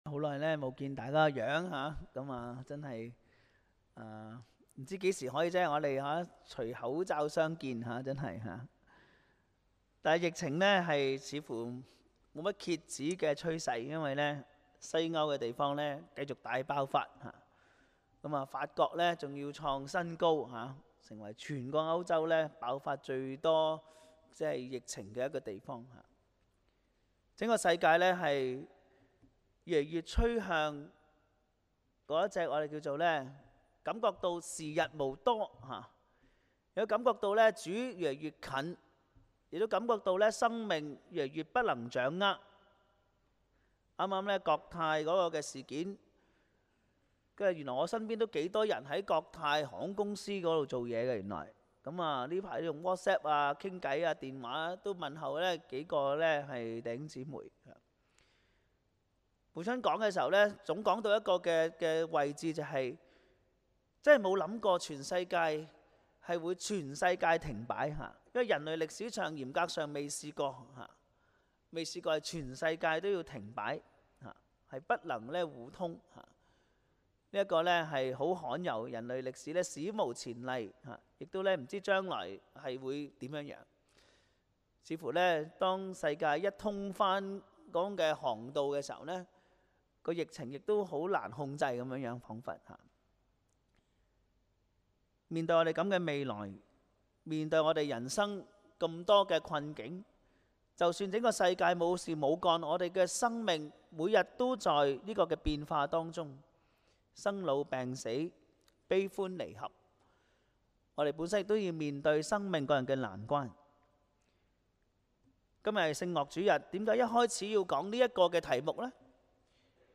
香港基督教循道衛理聯合教會: 講道重溫